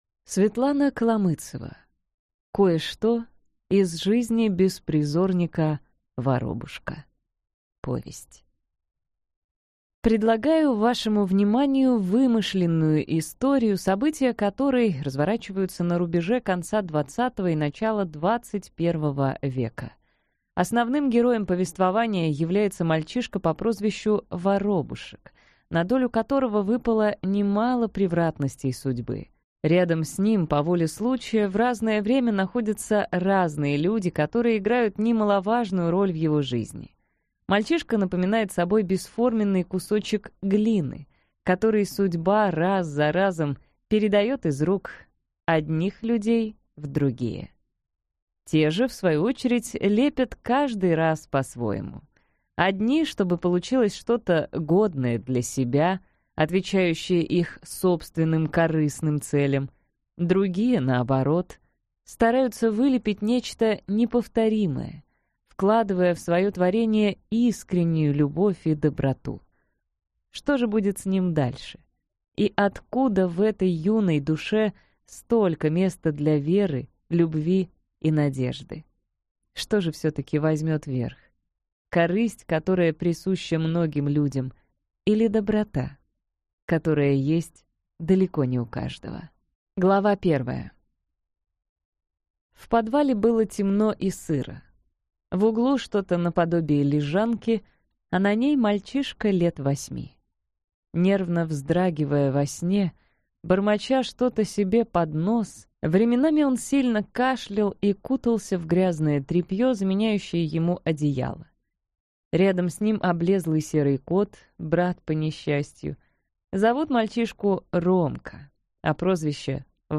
Аудиокнига Кое-что из жизни беспризорника Воробушка.